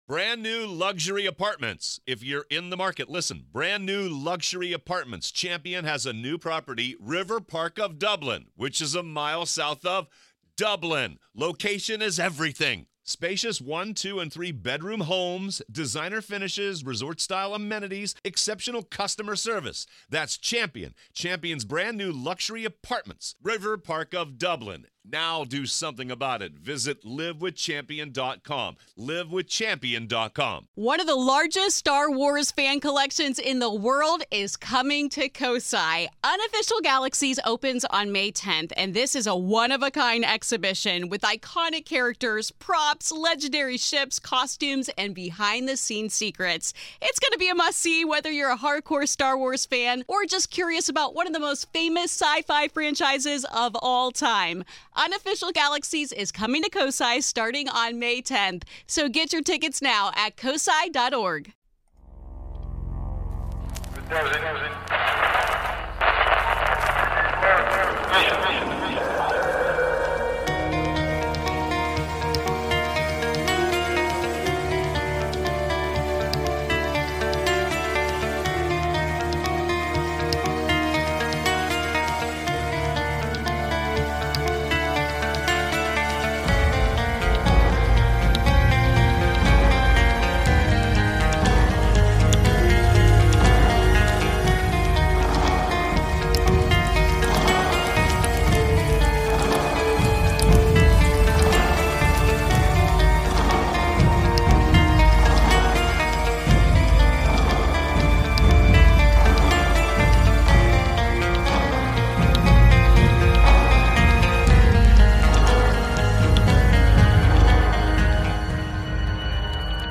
BONUS EPISODE - Roundtable discussion on the 1975 documentary “The Mysterious Monsters”
***WARNING*** Some colorful language may be used.